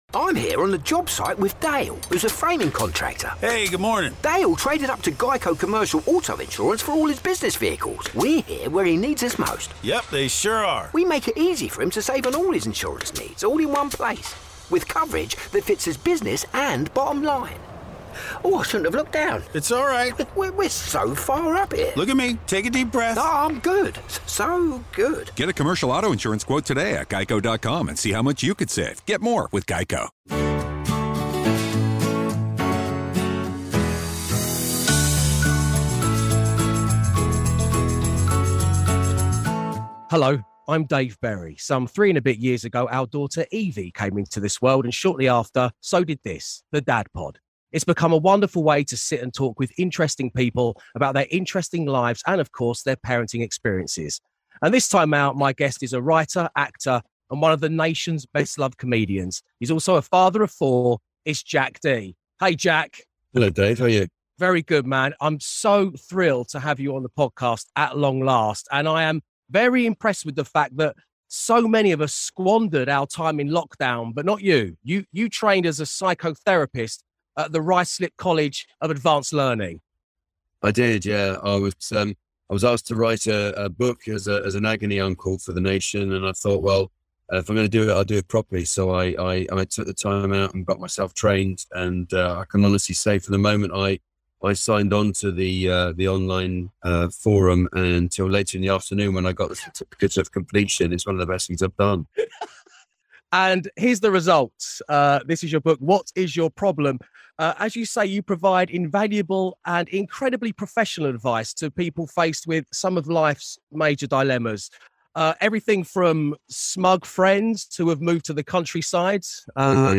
Join Absolute Radio's Dave Berry as he chats to a different guest each episode to talk about all things involved in being a dad, sharing their experiences of fatherhood (or motherhood in some guests' cases), their relationship with their own fathers, as well gathering some advice (or 'Dad-vice') along the way.